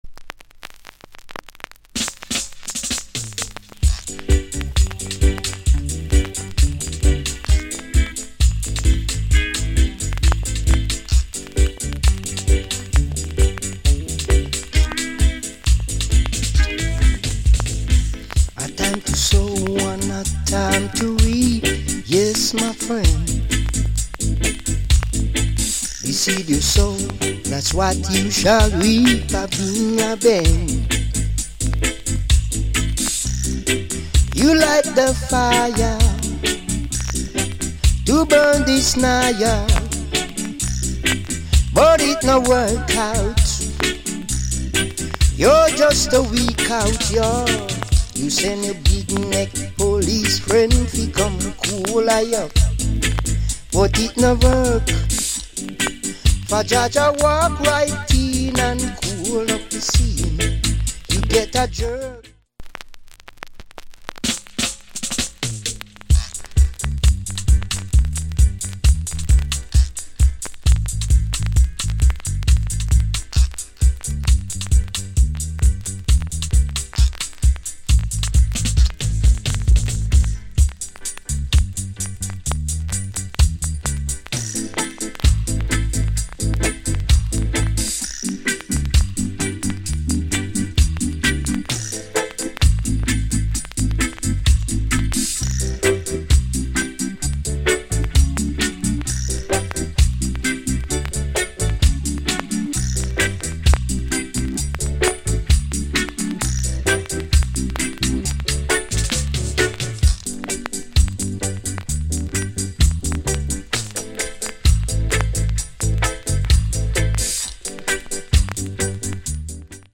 Genre Reggae70sLate / [A] Male Vocal [B] Male DJ